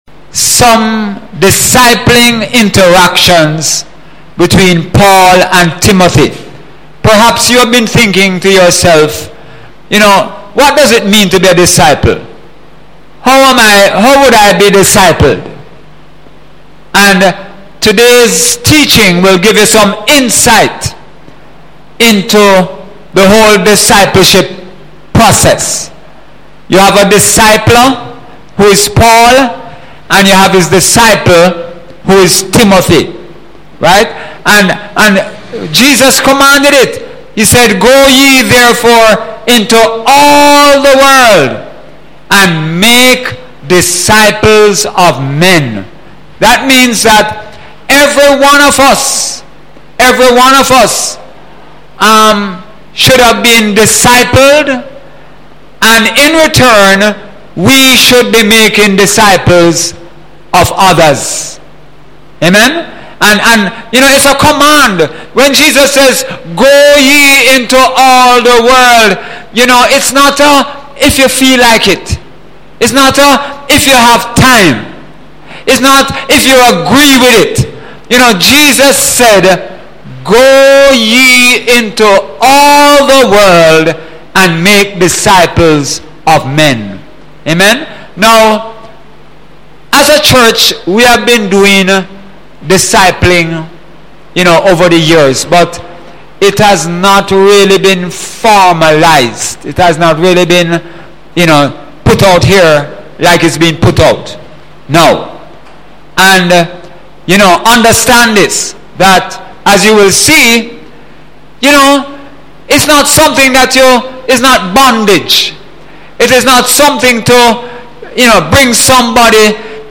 Sunday Sermon – Discipling Between Paul & Timothy, March 12, 2017